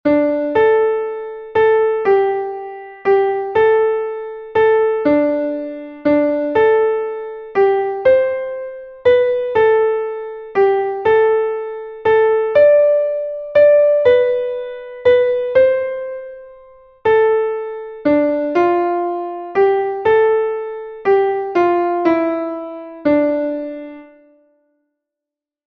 traditionsreiches Volkslied